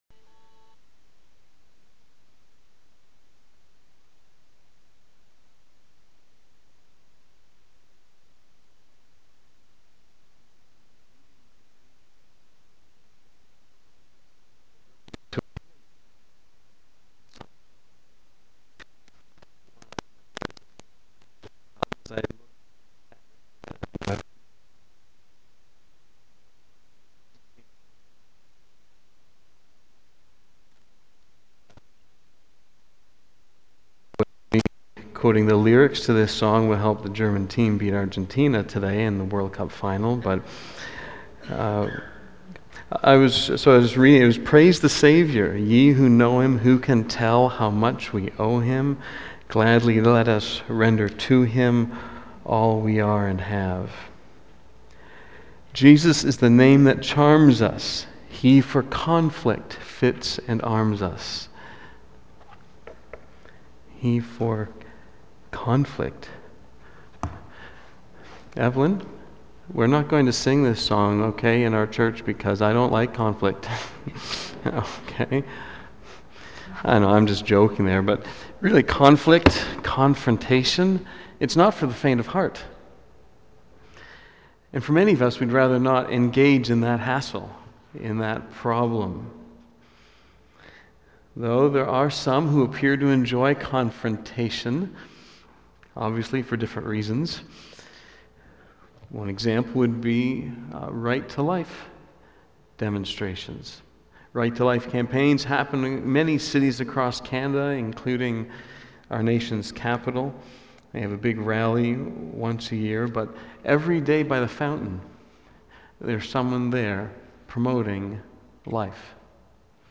2014 Admonish One Another BACK TO SERMON LIST Preacher